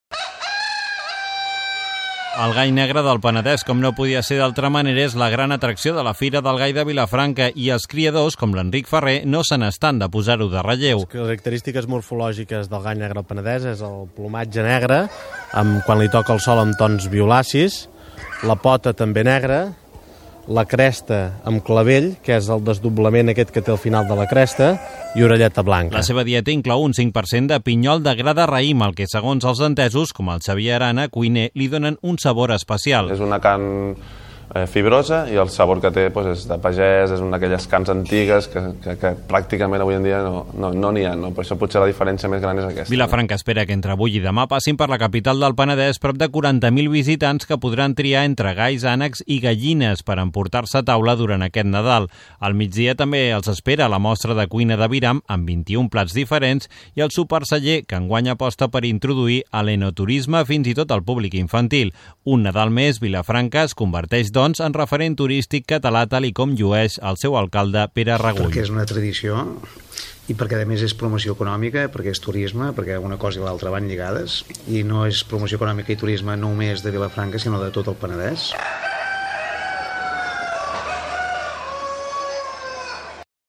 Fira del Gall de Vilafranca del Penedès (declaració de l'alcalde Pere Rebull) Gènere radiofònic Informatiu